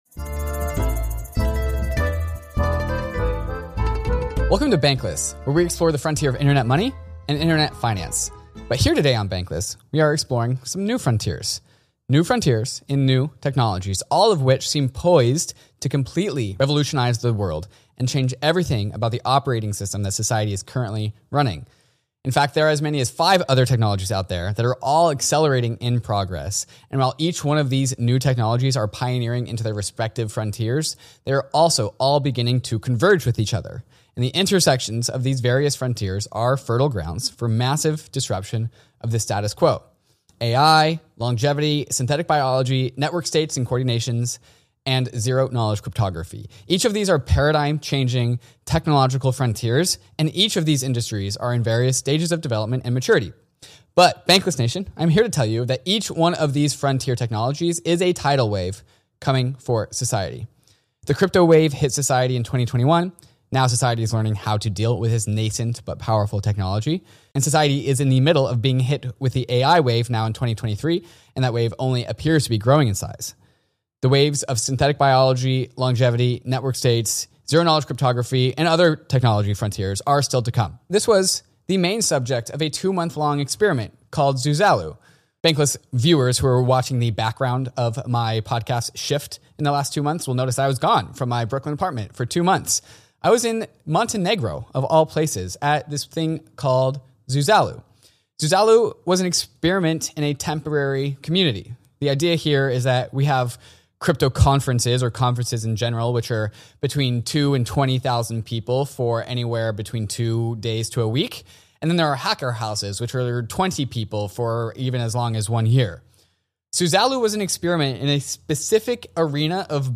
Guest Vitalik Buterin